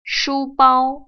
[shūbāo] 수빠오